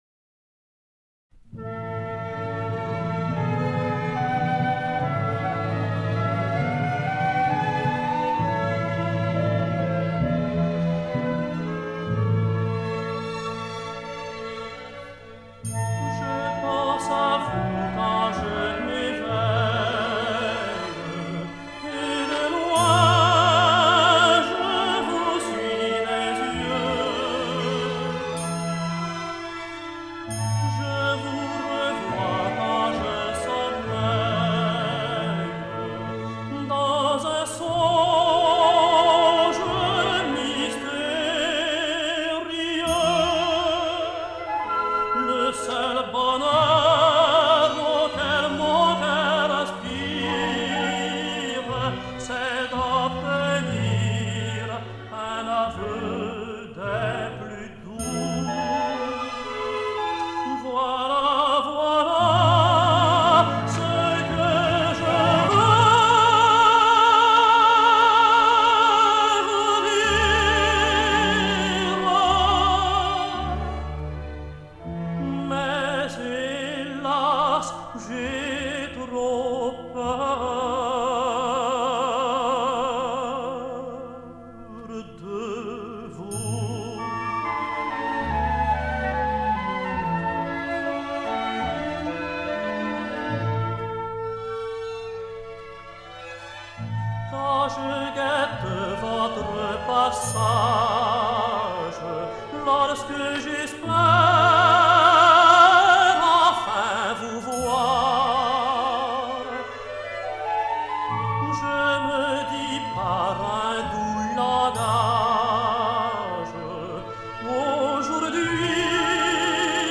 André Mallabrera singsMaître Pathelin:
Weird orchestral accompaniment, but astounding singing.